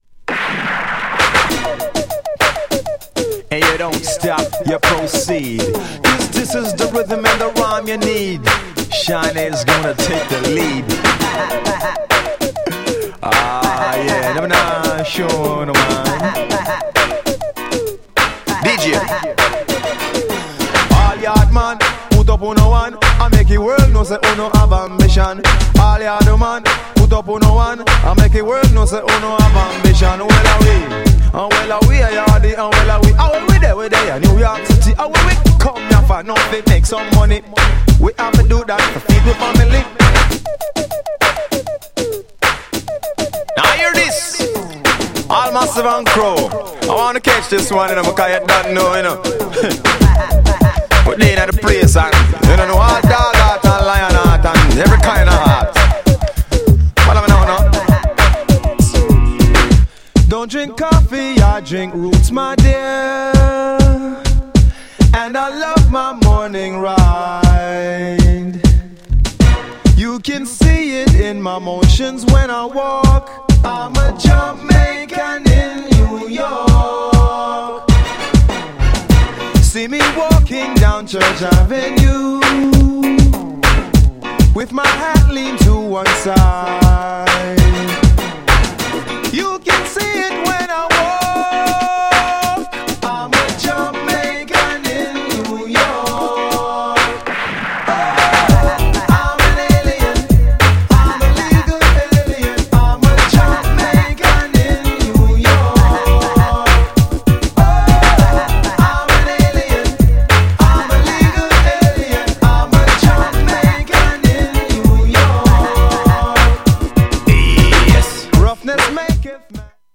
GENRE R&B
BPM 91〜95BPM
# feat.にラガ
# POPな歌モノ
# キャッチーなR&B
# レゲエ # レゲエテイスト